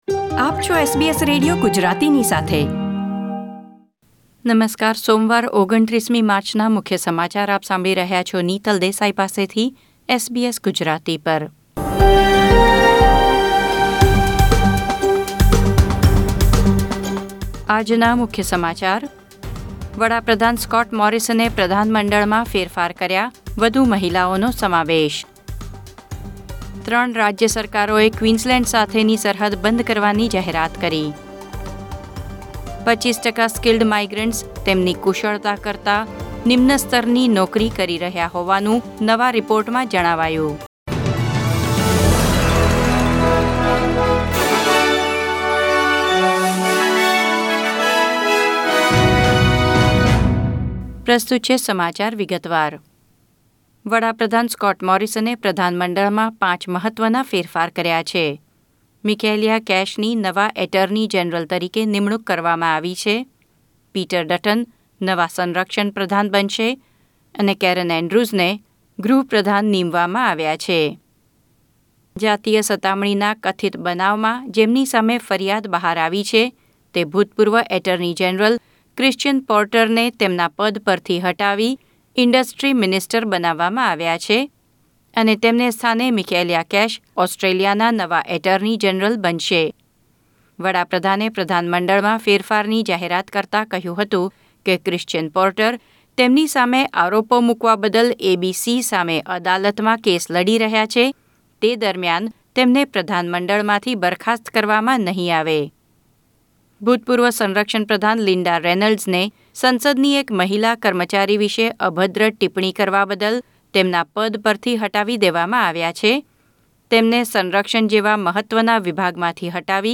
૨૯ માર્ચ ૨૦૨૧ના મુખ્ય સમાચાર